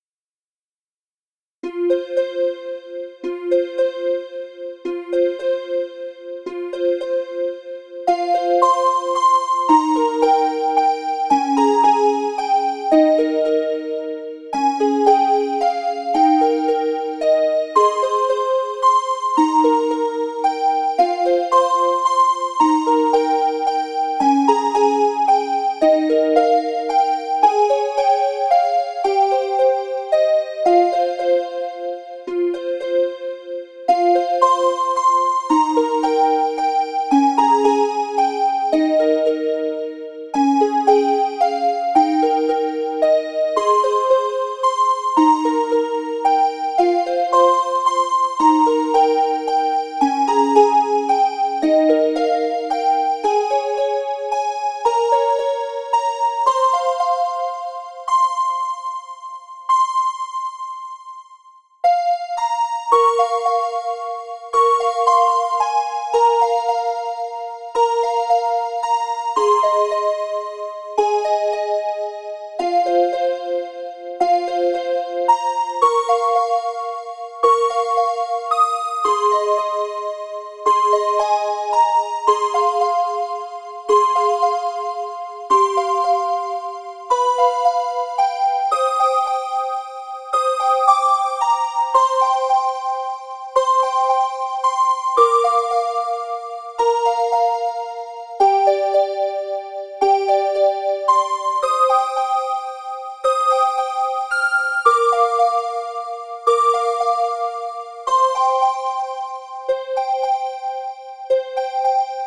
・１曲目のオルゴール版です。
frogwaltz_piano3_harp4_long.mp3